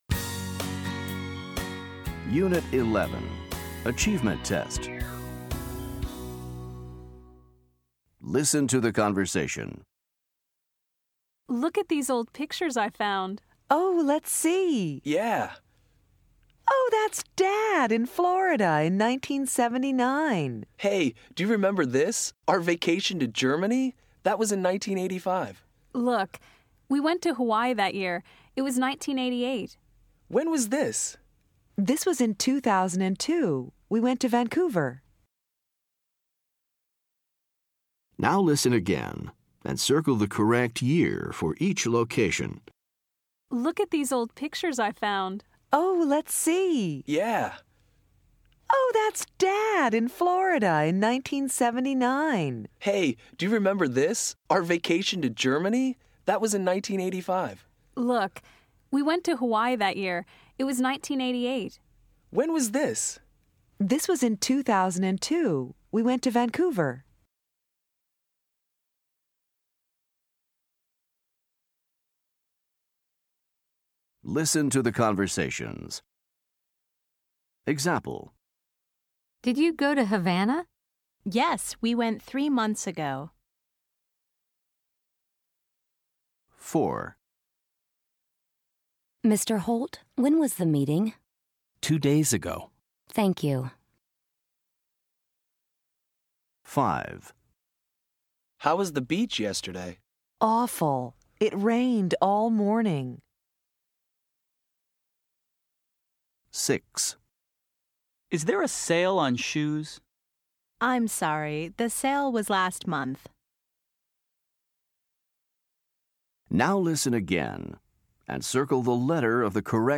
Listen to the conversation. Then listen again and choose the correct year for each location.